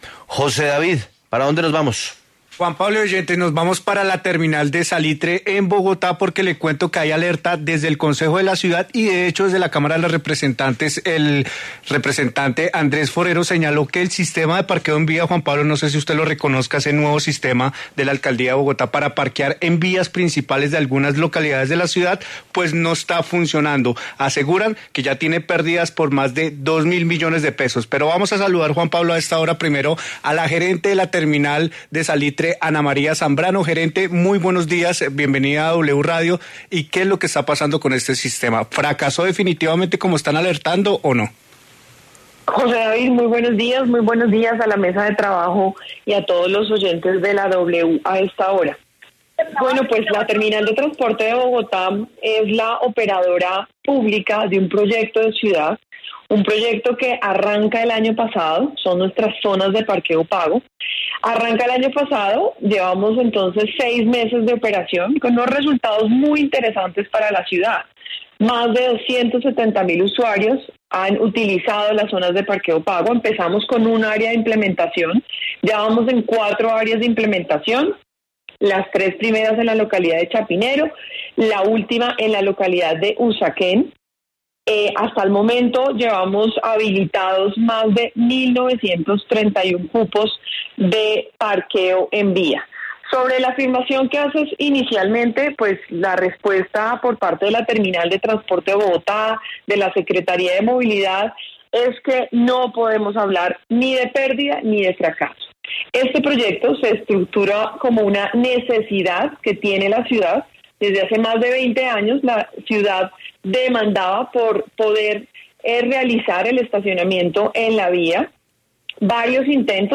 En diálogo con La W, Ana Zambrano, gerente de la Terminal de Transportes de Bogotá; y el concejal Rolando González se pronuncian sobre el sistema de parqueo en vía en Bogotá.